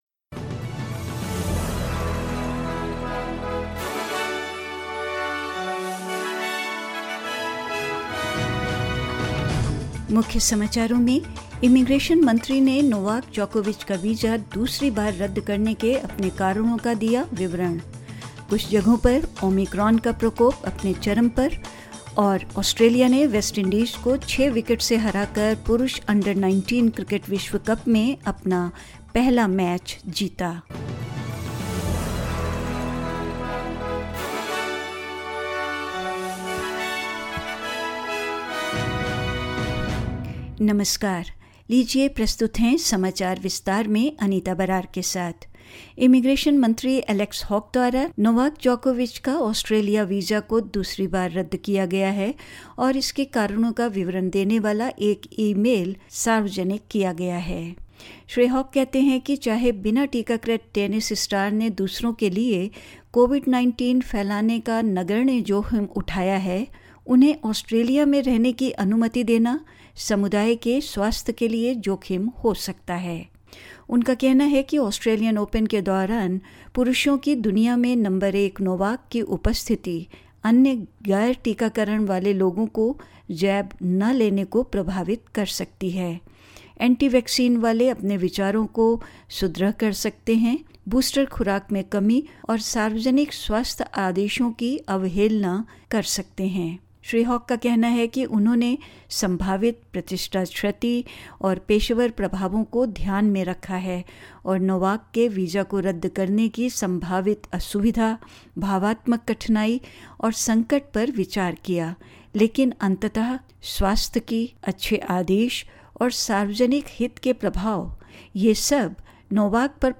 In this latest SBS Hindi bulletin: The immigration minister details his reasons for cancelling Novak Djokovic's visa for a second time; Omicron outbreaks nearing their peak in some jurisdictions; Australia wins its first Men's Under-19s Cricket World Cup match, beating the West Indies by six wickets and more news.